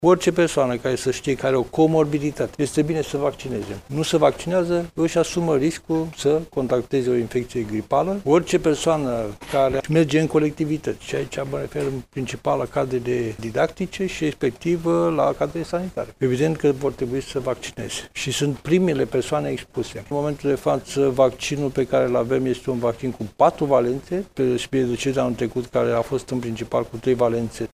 La rândul său, prof.dr. Adrian Streinu Cercel, prezent, astăzi, la Iaşi, a arătat că cele mai expuse sunt persoanele care sufer de boli cronice, precum şi cele care lucrează în colectivităţi: